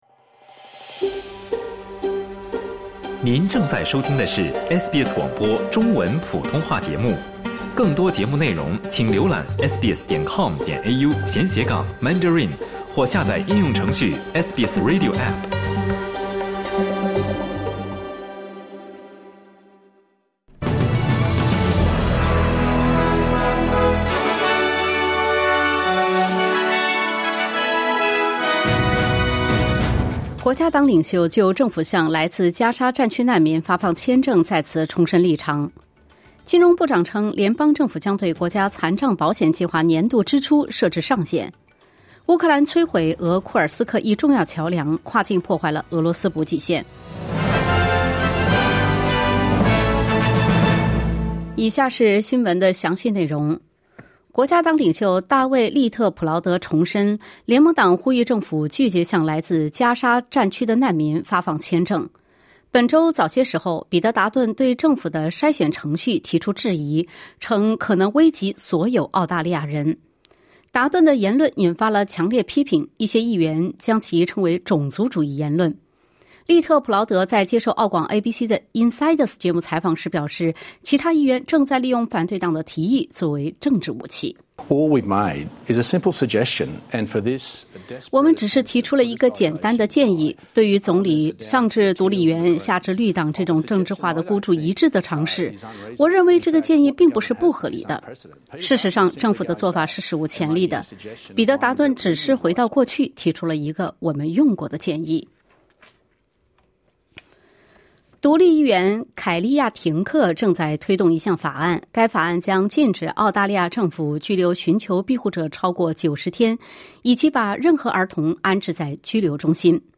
SBS早新闻（2024年8月19日）